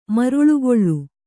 ♪ maruḷugoḷḷu